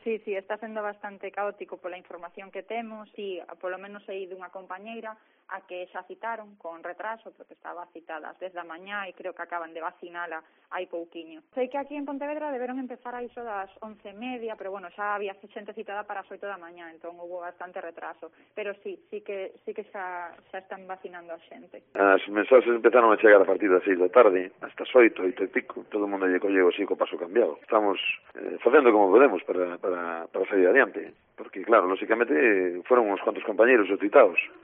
Docentes de Marín y Poio dan fe de la premura de la primera jornada de vacunación